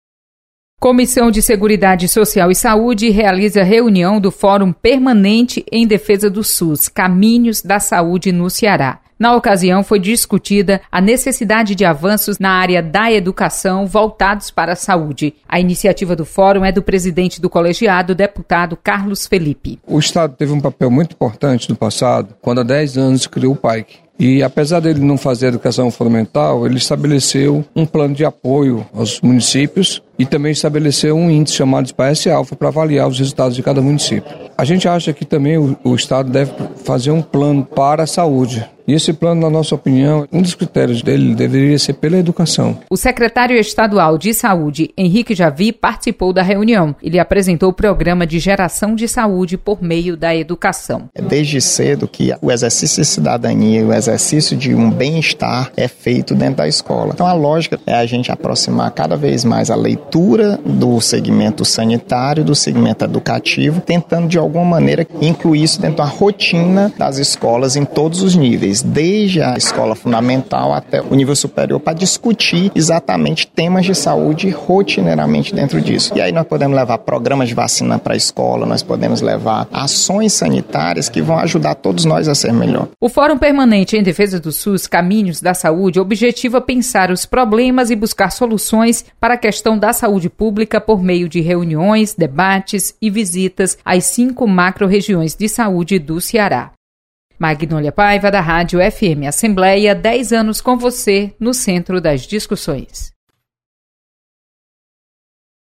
Assembleia sedia reunião do Fórum Permanente em Defesa do SUS. Repórter